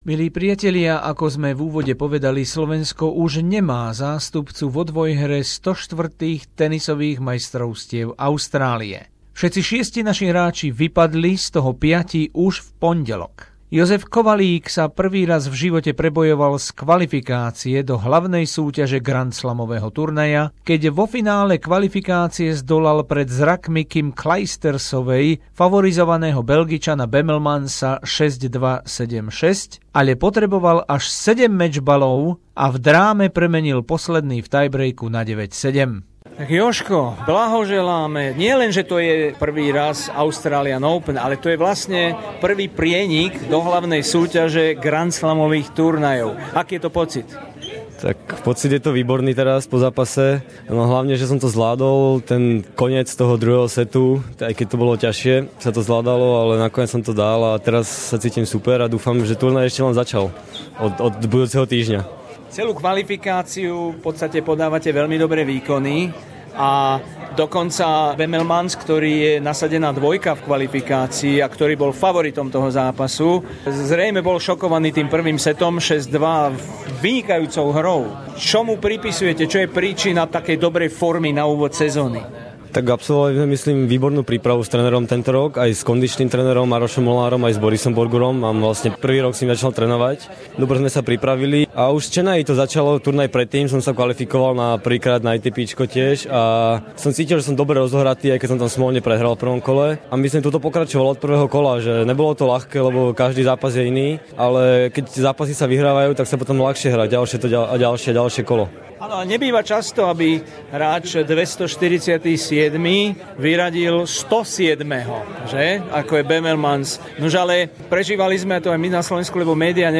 Reportáž z prvého týždňa Australian Open 2016 s rozhovormi so slovenskými tenistami